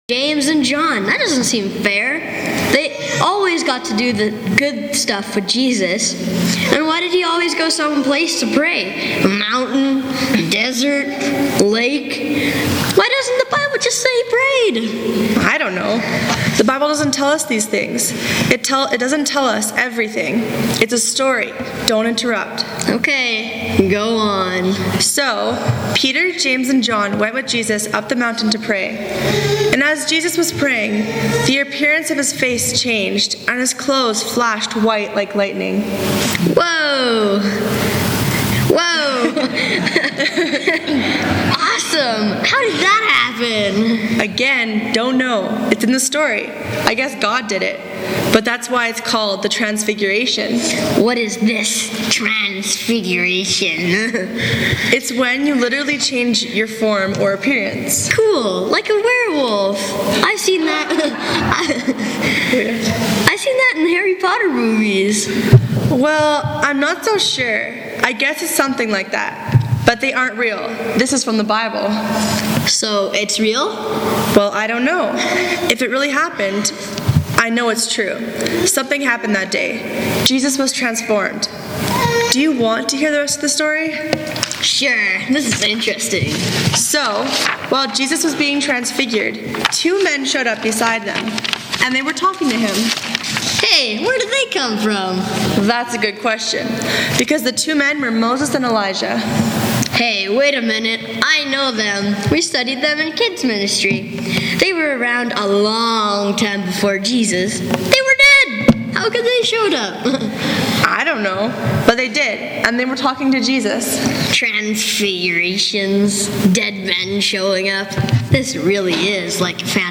Sermons | Okotoks United Church